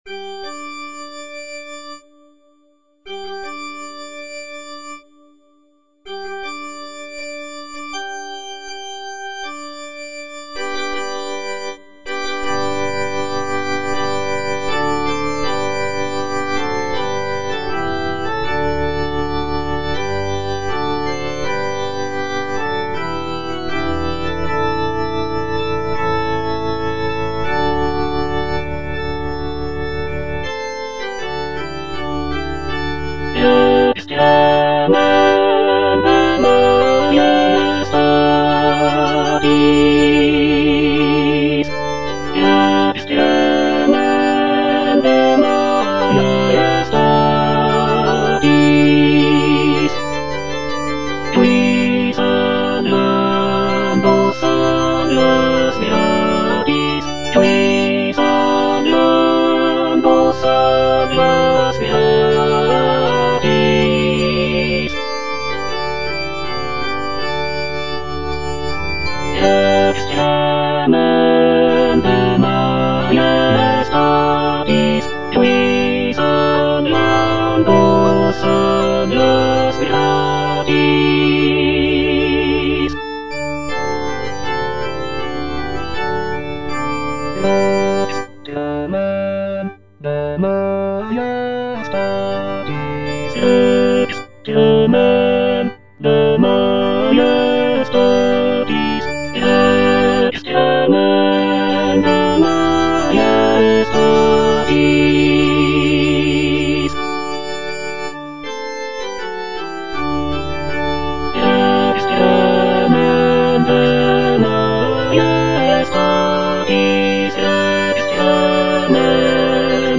(tenor I) (Emphasised voice and other voices) Ads stop
is a sacred choral work rooted in his Christian faith.